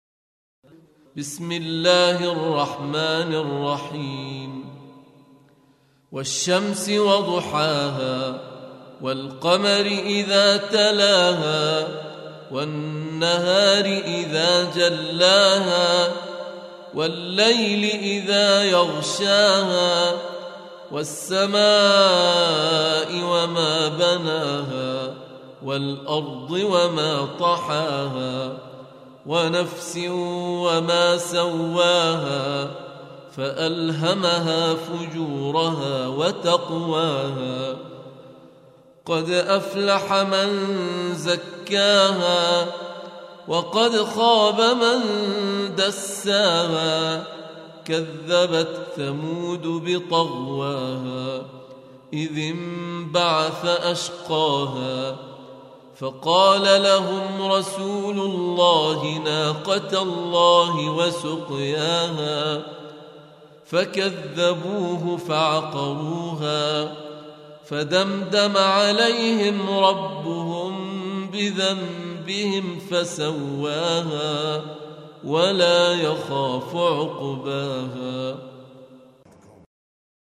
Surah Repeating تكرار السورة Download Surah حمّل السورة Reciting Murattalah Audio for 91. Surah Ash-Shams سورة الشمس N.B *Surah Includes Al-Basmalah Reciters Sequents تتابع التلاوات Reciters Repeats تكرار التلاوات